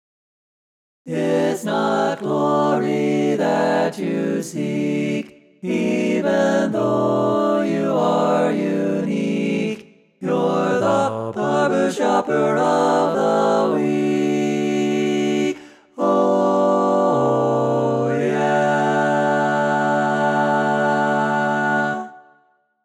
Key written in: D Major
Type: Barbershop